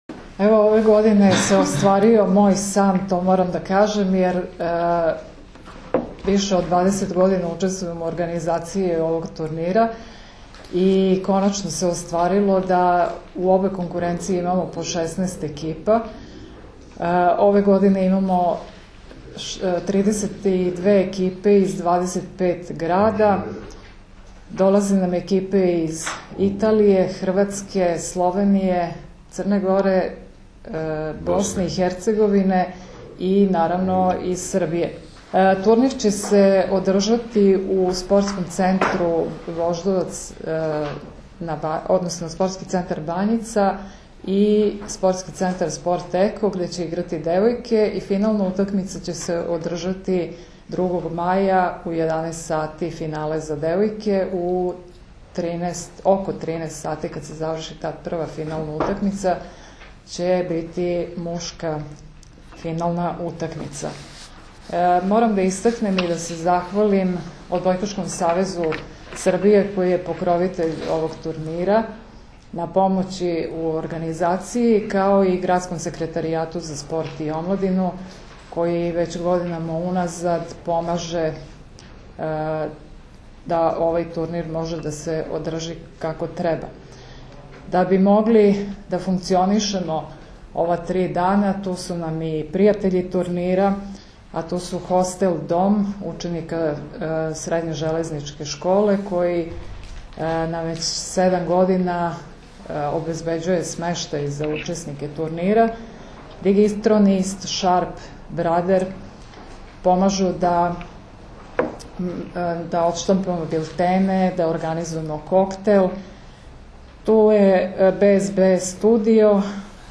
U beogradskom hotelu “Belgrade City” danas je održana konferencija za novinare povodom 51. Međunarodnog “Majskog turnira” 2016, koji će se odigrati od 30. aprila – 2. maja.
IZJAVA